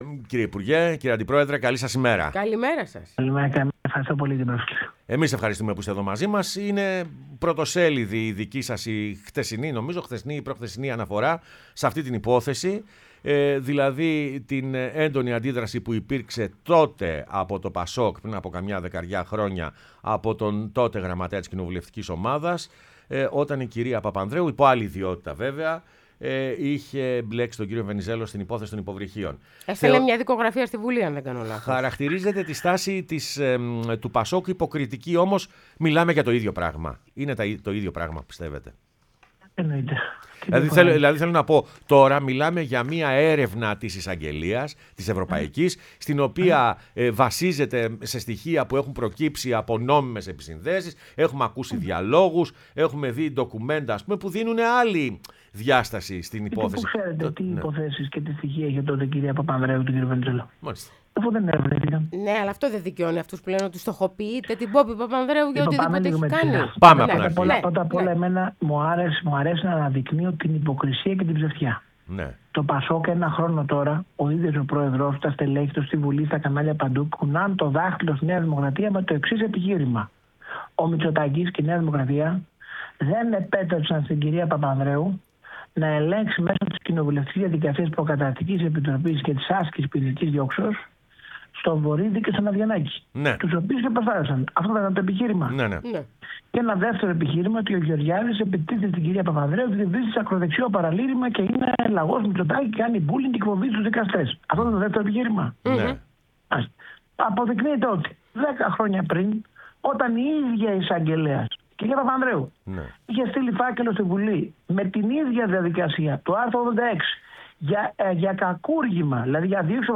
O Άδωνις Γεωργιάδης, Υπουργός Υγείας και αντιπρόεδρος ΝΔ, μίλησε στην εκπομπή «Πρωινές Διαδρομές»